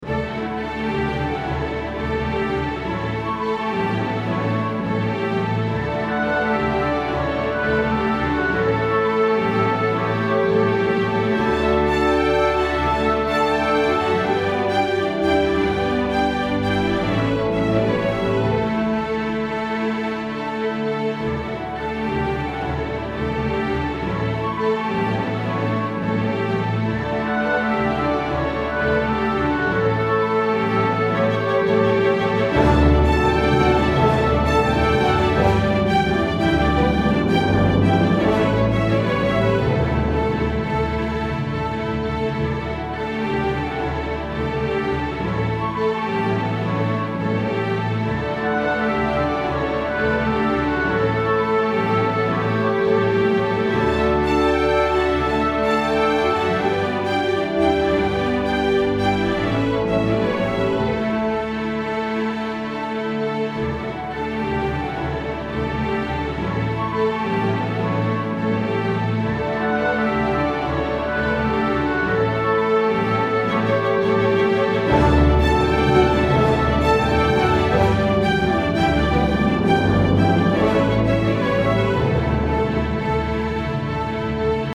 make it classical